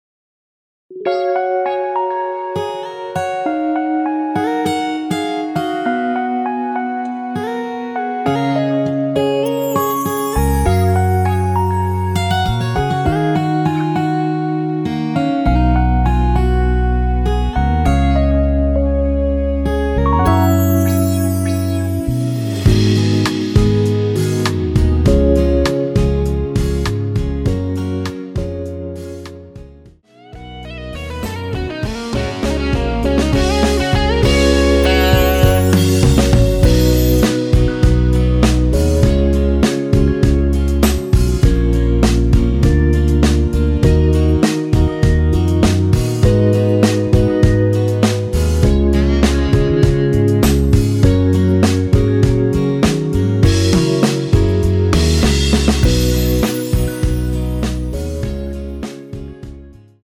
대부분의 남성분이 부르실수 있도록 제작 하였습니다.(미리듣기 참조)
F#
앞부분30초, 뒷부분30초씩 편집해서 올려 드리고 있습니다.
중간에 음이 끈어지고 다시 나오는 이유는